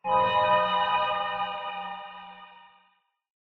ambient / cave
cave1_fixed.ogg